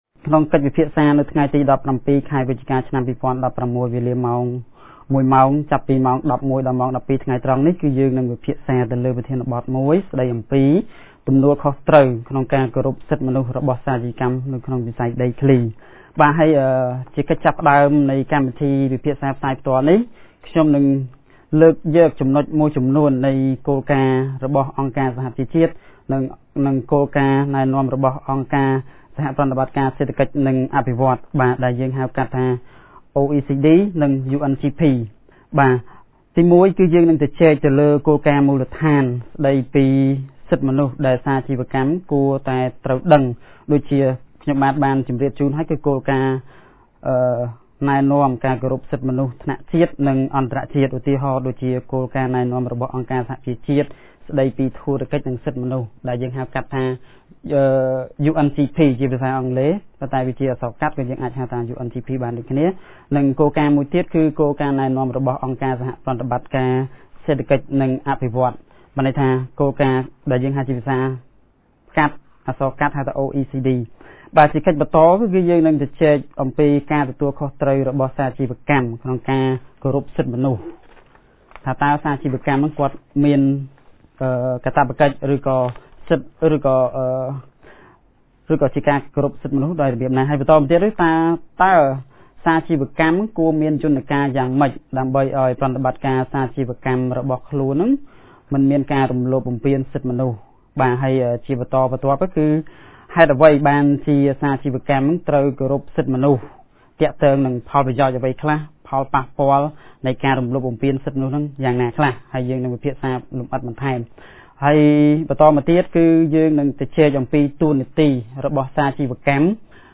On Thursday 17th November 2016, CCHR's Radio Programe held a talk show on the topic" The responsibility of corporate actors to respect human rights in the land sector ".